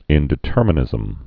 (ĭndĭ-tûrmə-nĭzəm)